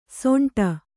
♪ soṇṭa